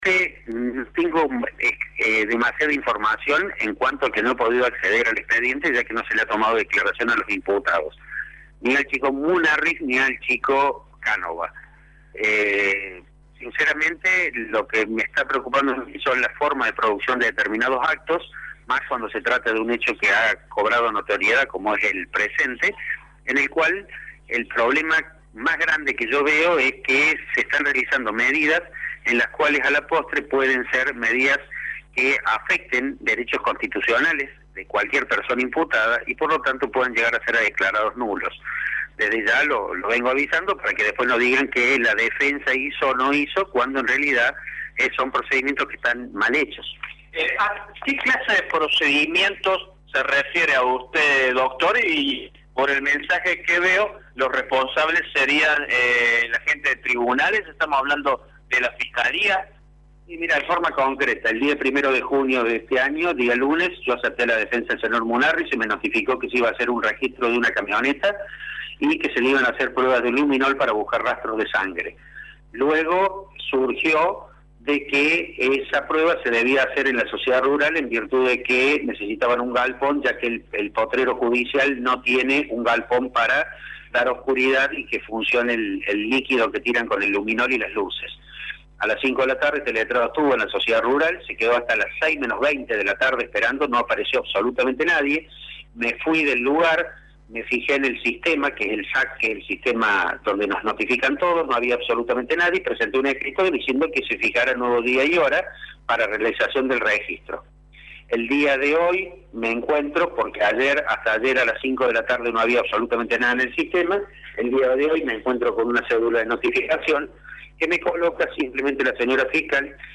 En diálogo con Radio Show